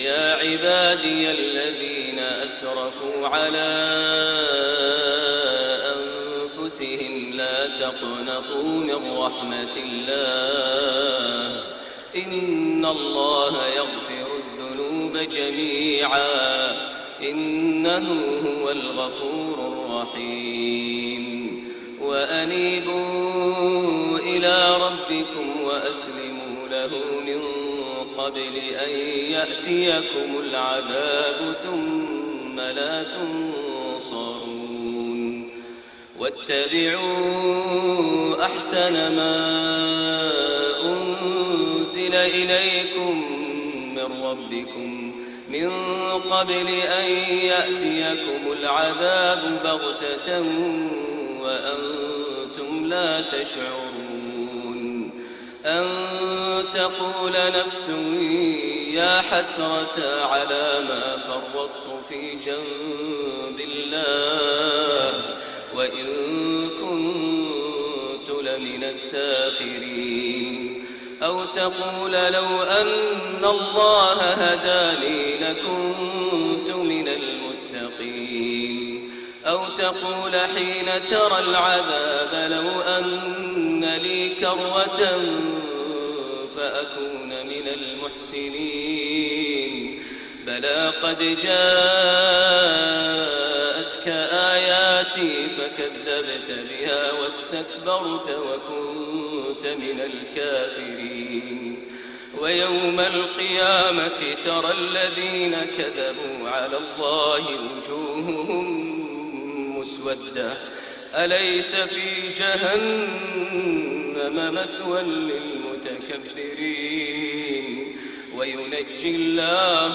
فجر يوم عرفة | ٩ ذي الحجة ١٤٣٢هـ خواتيم سورة الزمر > فجريات يوم عرفة > المزيد - تلاوات ماهر المعيقلي